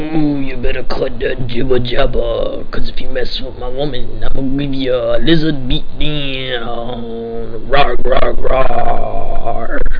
godzilla1.wav